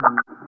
23 WAH    -R.wav